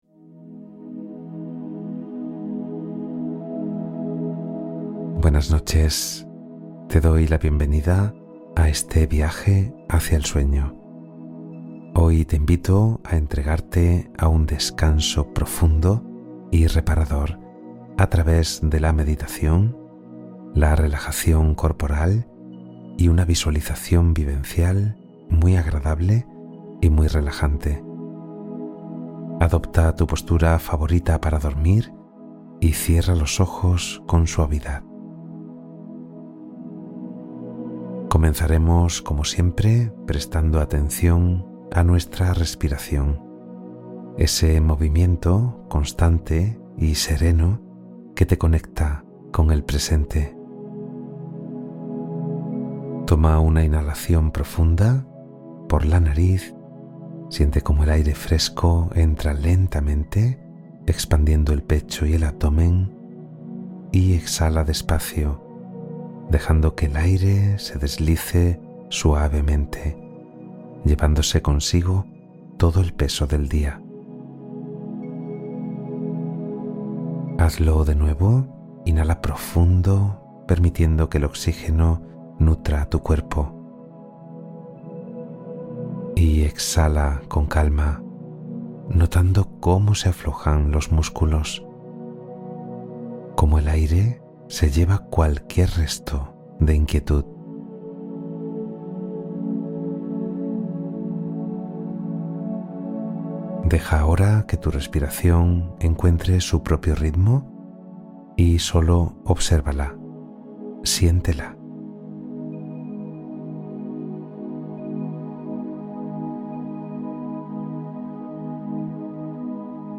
Relato Guiado de Ritmo Lento para Apagar la Activación Mental